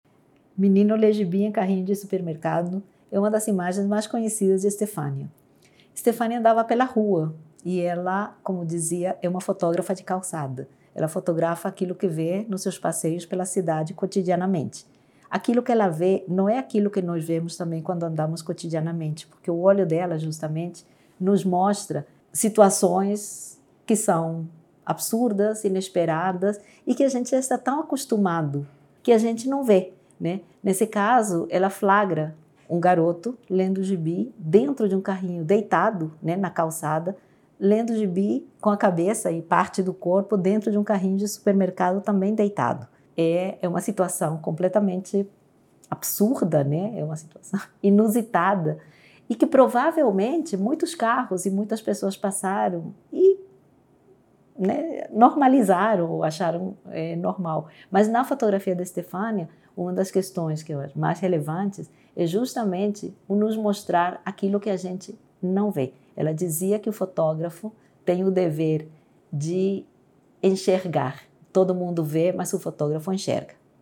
Faixa 2 - Comentário da curadoria